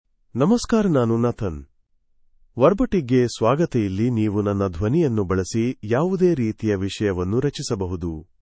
NathanMale Kannada AI voice
Nathan is a male AI voice for Kannada (India).
Voice sample
Listen to Nathan's male Kannada voice.
Male